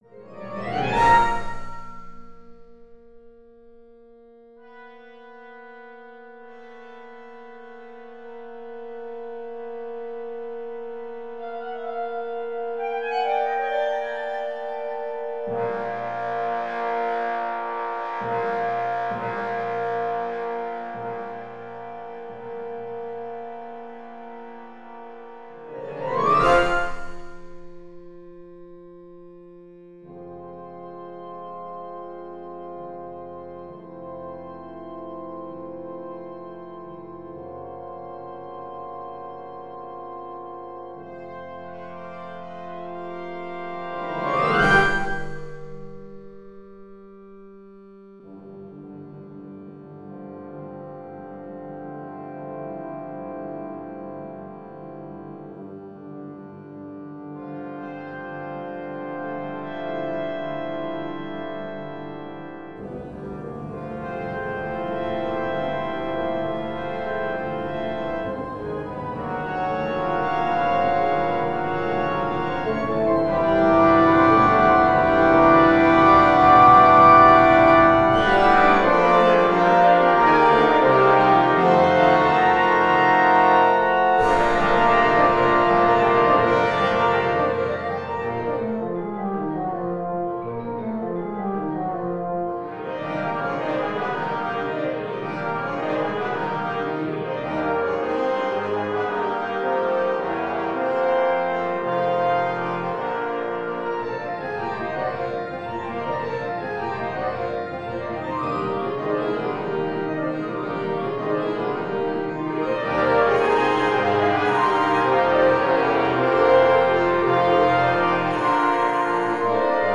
prelude for symphonic wind orchestra